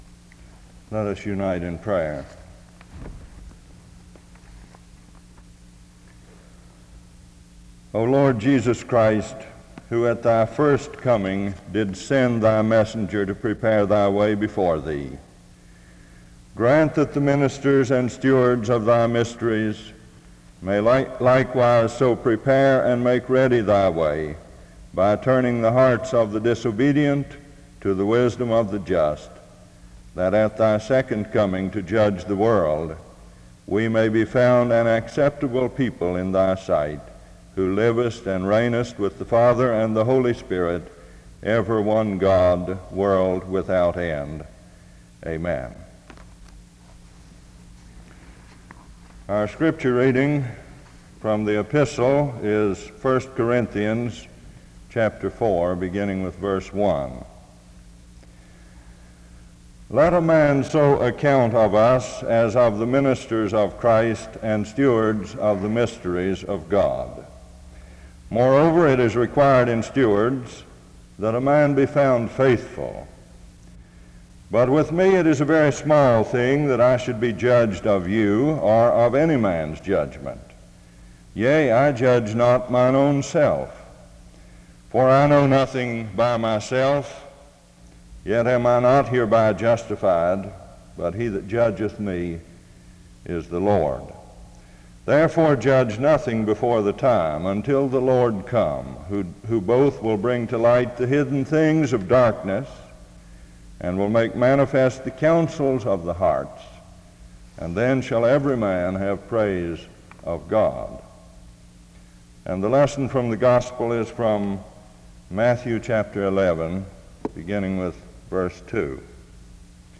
The service begins in a word of prayer from 0:00-0:47. 1 Corinthians 4:1-5 is read from 0:49-1:51. Matthew 11:2-10 is read from 1:52-3:24.